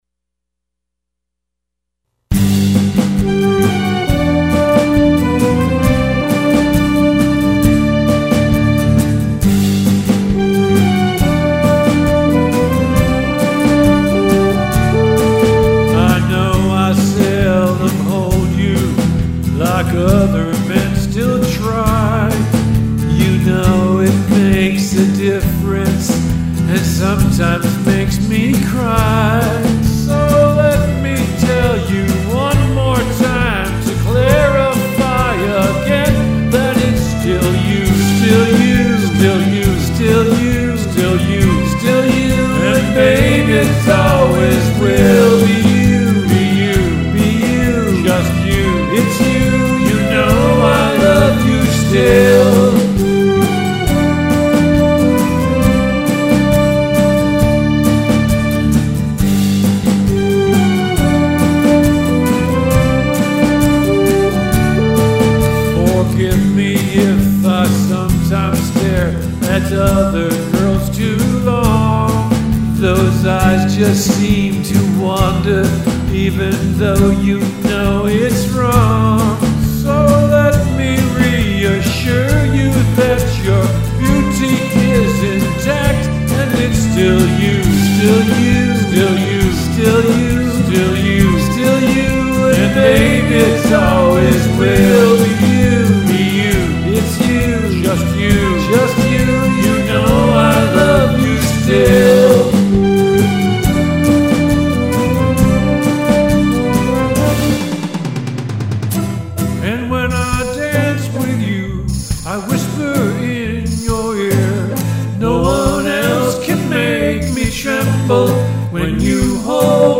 horn solos